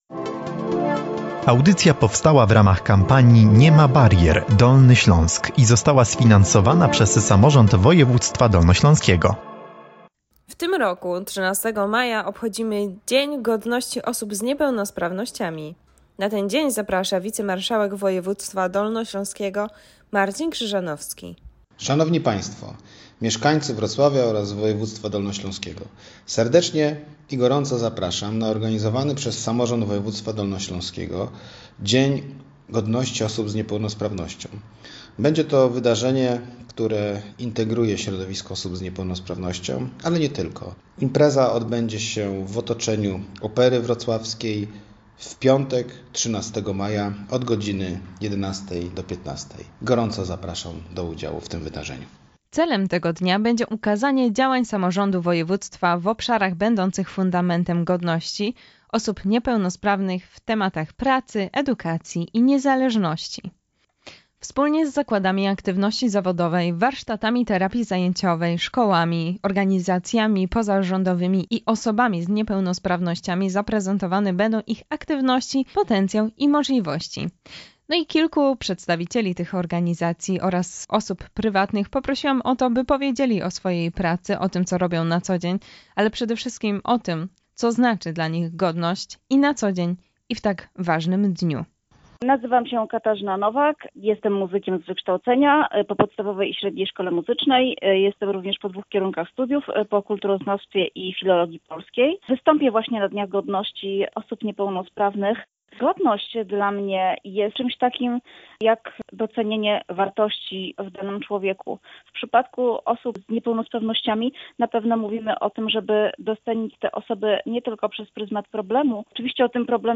Posłuchaj, co o godności mówią przedstawiciele organizacji, które będą uczestniczyć w obchodach Dnia Godności, a także osoby prywatne.